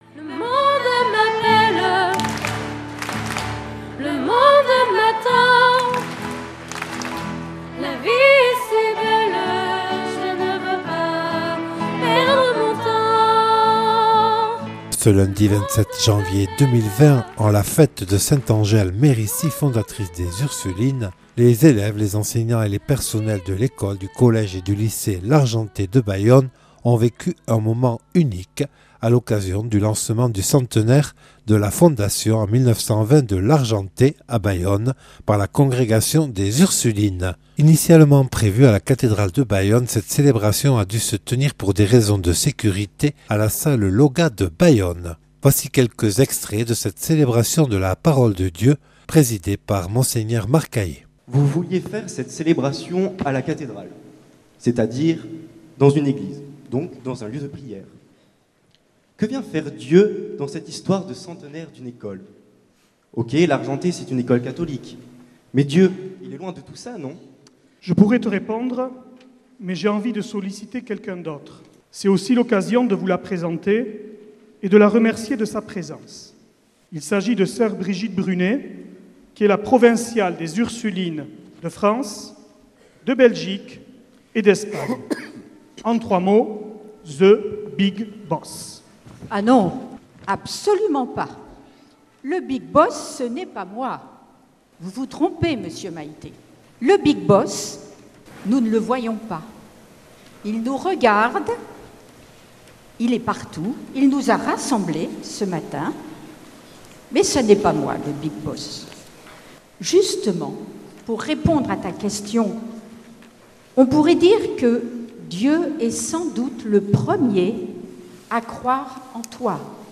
Le lancement du centenaire de l’ensemble Scolaire Largenté de Bayonne le 27 janvier 2020 à la salle Lauga de Bayonne